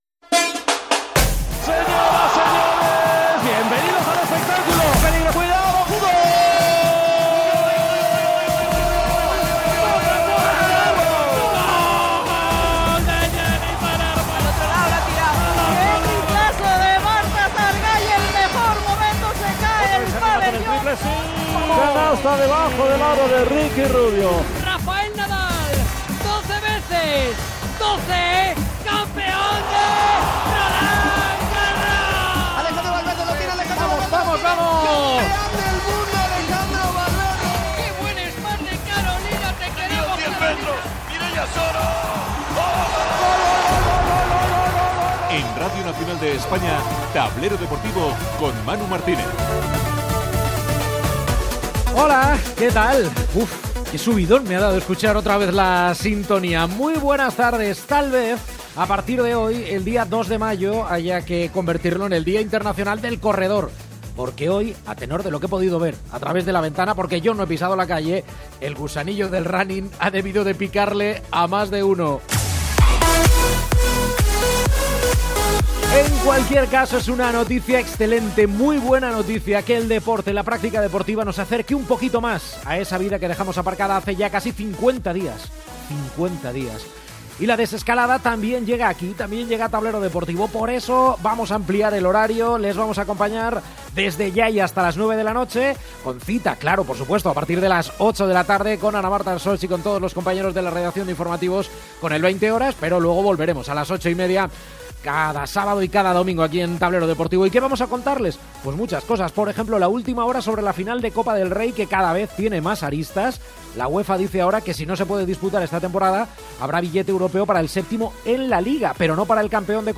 Careta, presentació del programa el primer dia de l'inici de la desescalada del confinament de la pandèmia de la Covid 19.
Diàleg dels presentadors.